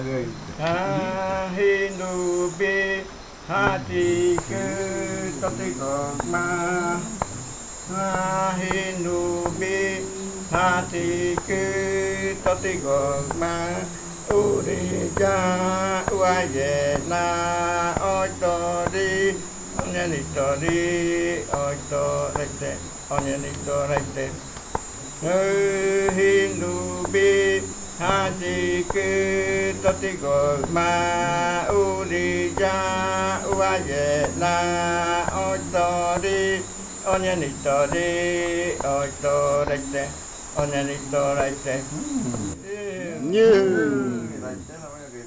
Leticia, Amazonas, (Colombia)
Grupo de danza Kaɨ Komuiya Uai
Canto fakariya de la variante Muruikɨ (cantos de la parte de arriba) Esta grabación hace parte de una colección resultante del trabajo de investigación propia del grupo de danza Kaɨ Komuiya Uai (Leticia) sobre flautas y cantos de fakariya.
Fakariya chant of the Muruikɨ variant (Upriver chants) This recording is part of a collection resulting from the Kaɨ Komuiya Uai (Leticia) dance group's own research on pan flutes and fakariya chants.